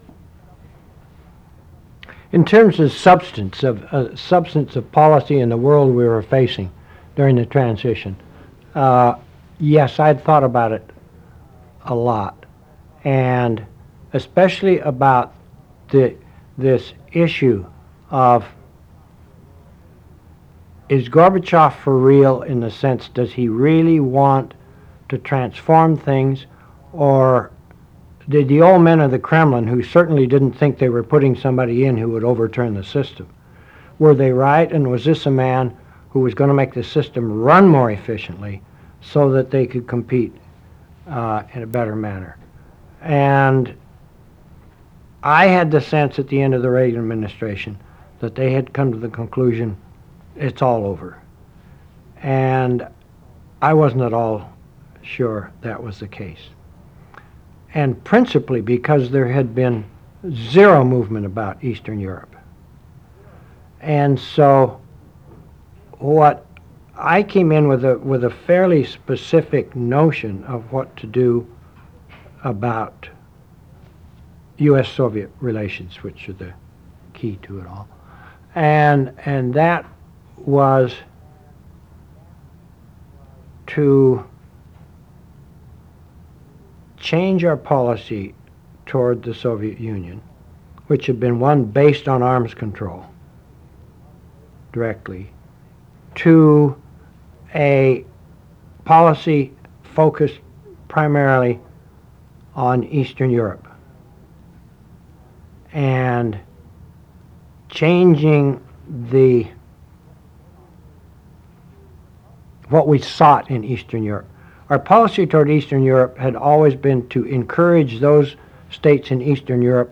'What We Sought in Eastern Europe' Photo: George H.W. Bush Presidential Library and Museum Brent Scowcroft was President George H. W. Bush’s national security advisor. He discusses his strategic thinking about Mikhail Gorbachev’s Soviet Union as the Bush administration started work at the end of the Cold War. Date: November 12, 1999 Participants Brent Scowcroft Associated Resources Brent Scowcroft Oral History Part I Brent Scowcroft Oral History Part II The George H. W. Bush Presidential Oral History Audio File Transcript